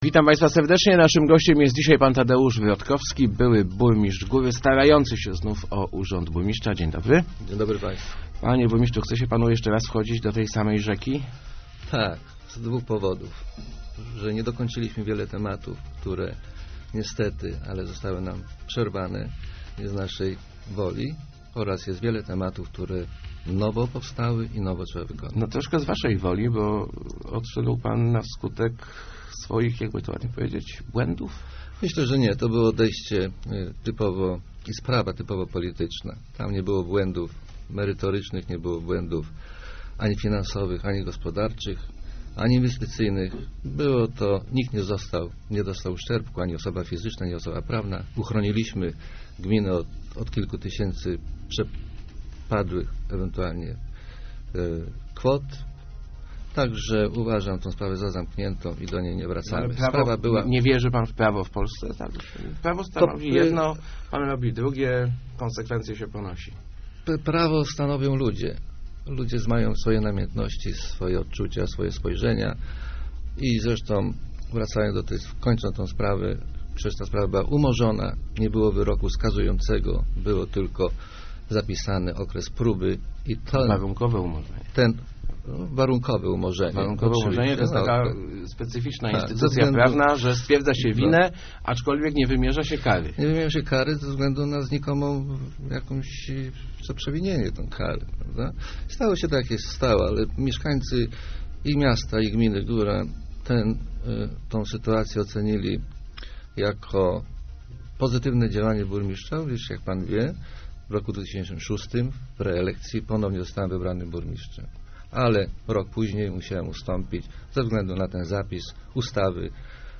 Jeżeli będę burmistrzem, nie przepuszczę ciężarówek przez Osetno, Starą Górę i Górę - zapowiada w Rozmowach Elki Tadeusz Wrotkowski. Były burmistrz chce wrócić na stanowisko, ponieważ uważa, że za jego czasów gmina dobrze się rozwijała.